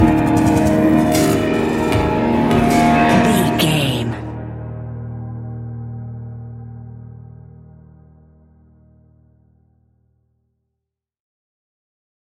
Epic / Action
Fast paced
In-crescendo
Ionian/Major
D♯
dark ambient
EBM
synths